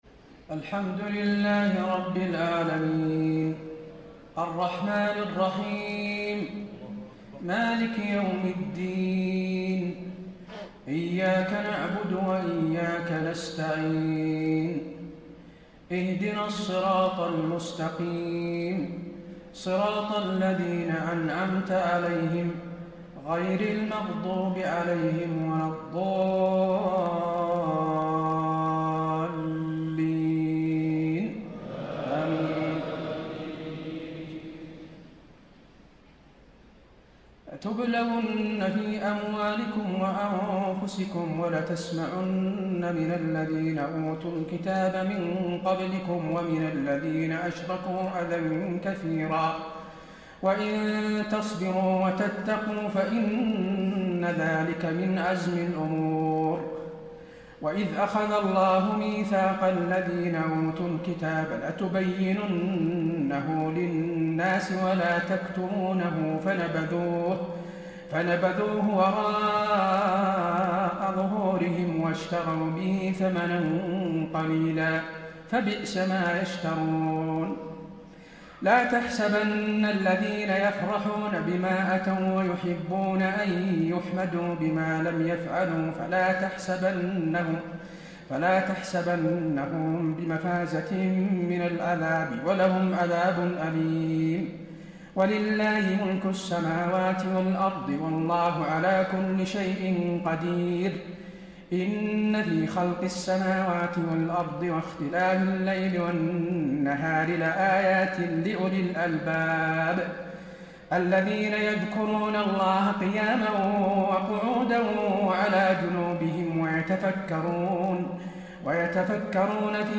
تهجد ليلة 24 رمضان 1434هـ من سورتي آل عمران (186-200) و النساء (1-22) Tahajjud 24 st night Ramadan 1434H from Surah Aal-i-Imraan and An-Nisaa > تراويح الحرم النبوي عام 1434 🕌 > التراويح - تلاوات الحرمين